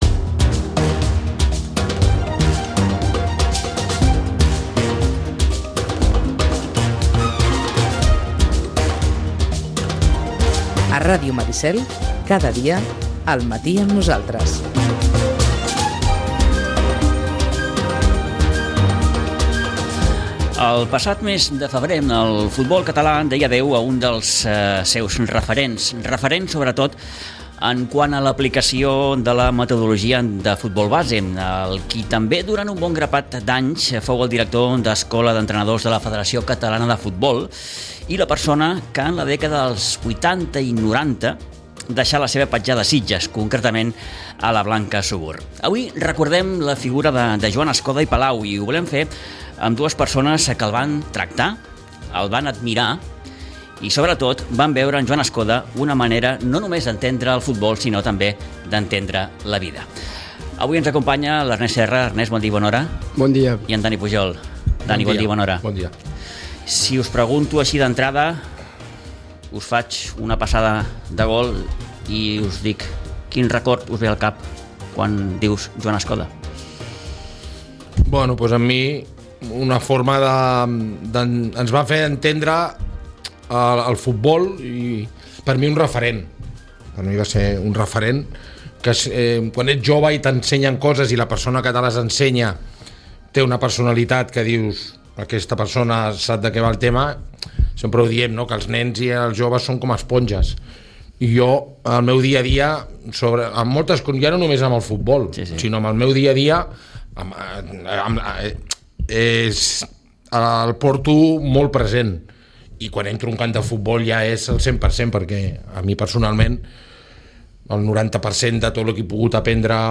Recordem en aquesta conversa